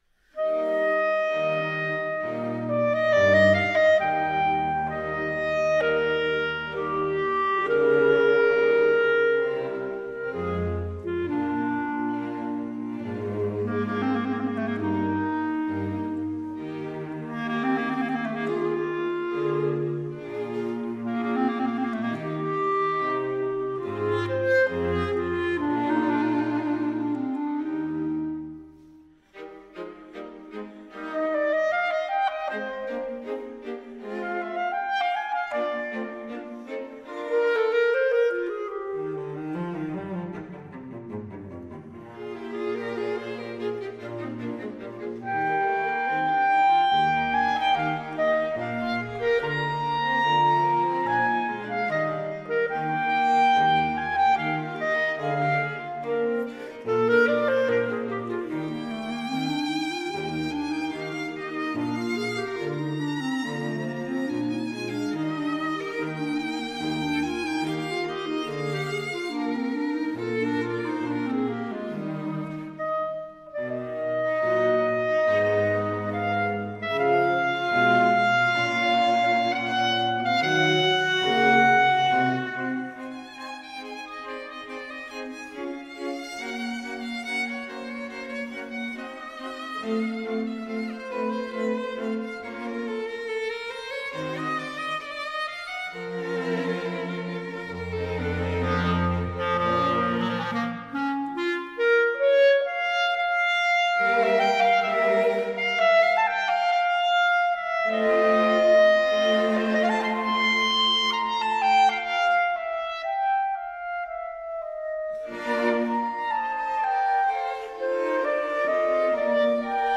Soundbite 2nd Movt